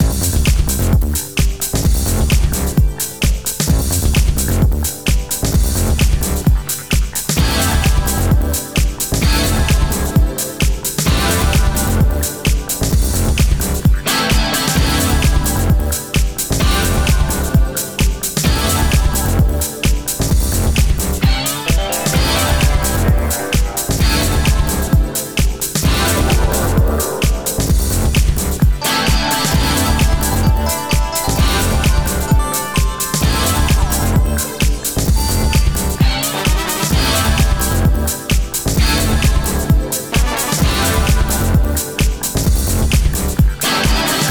Tecno dance (bucle)
Dance
repetitivo
sintetizador
Sonidos: Música